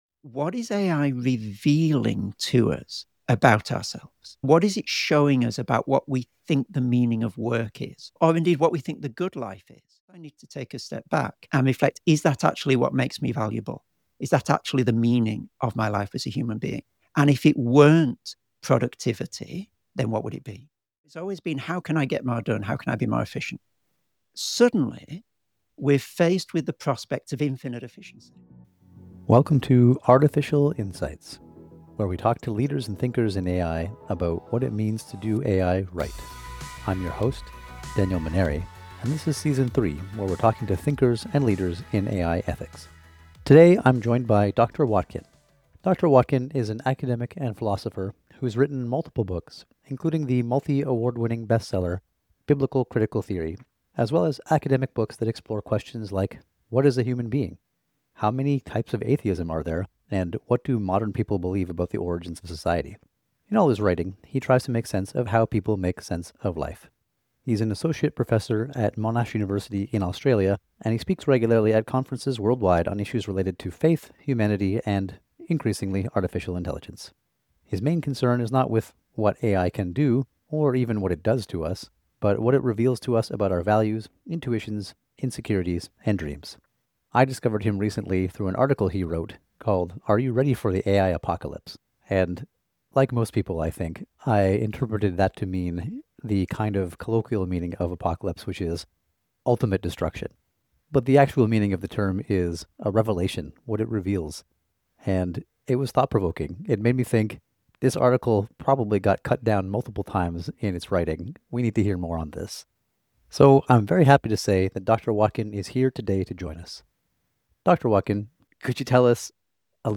Play Rate Listened List Bookmark Get this podcast via API From The Podcast 1 Candid conversations and real-world stories about building AI into products and businesses.